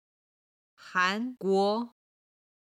韩国　(Hán guó)　韓国